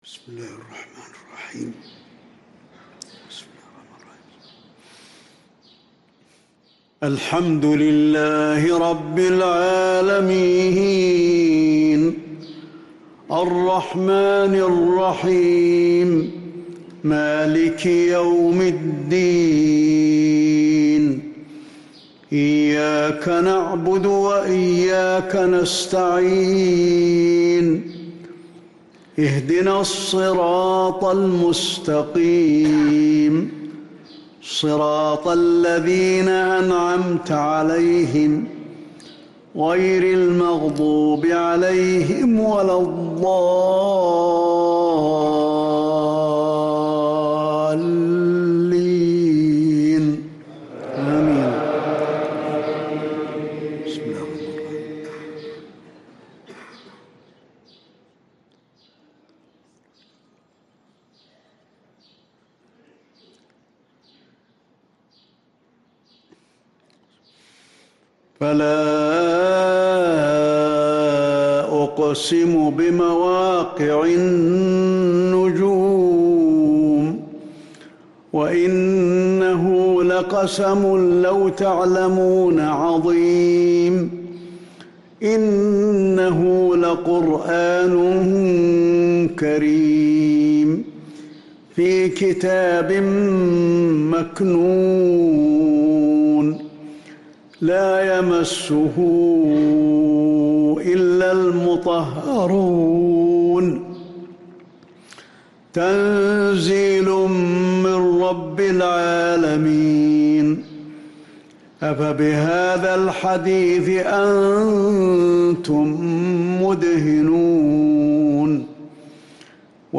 صلاة المغرب للقارئ علي الحذيفي 26 رجب 1445 هـ
تِلَاوَات الْحَرَمَيْن .